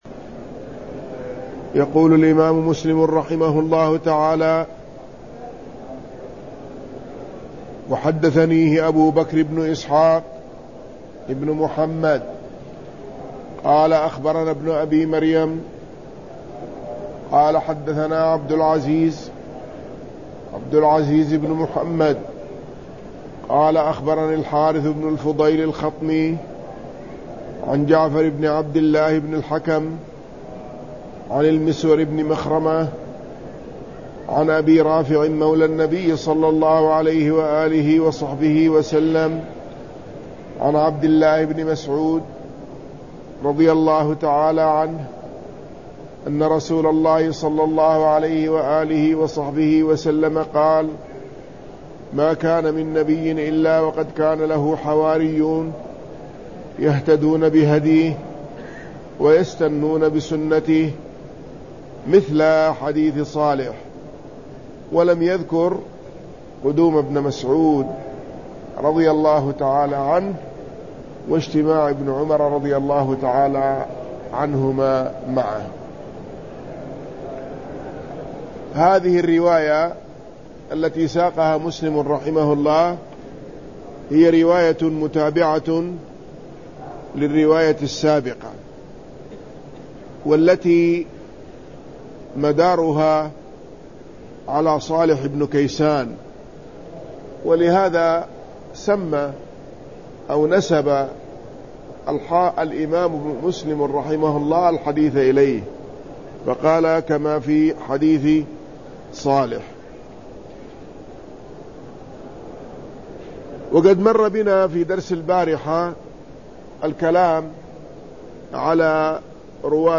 شرح صحيح مسلم الدرس 91: باب بيان كون النهي عن المنكر من الإيمان4